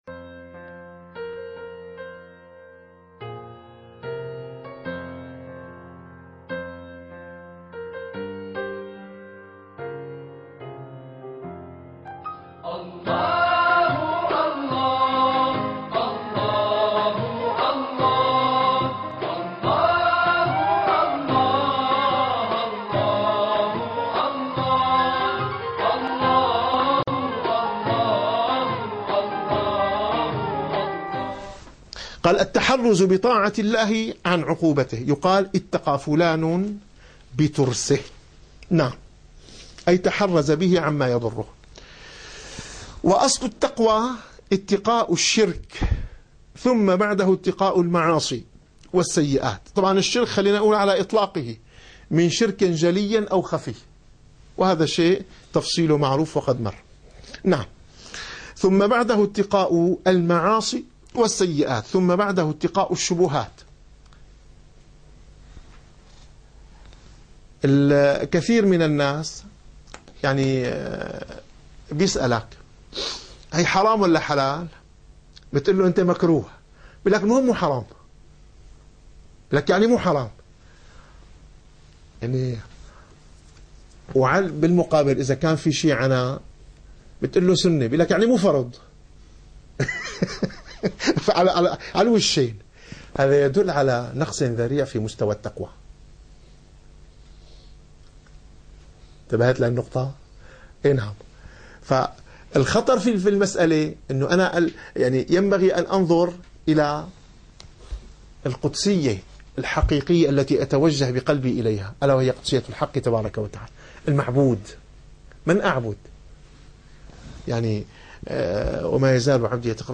- الدروس العلمية - الرسالة القشيرية - الرسالة القشيرية / الدرس الخامس والأربعون.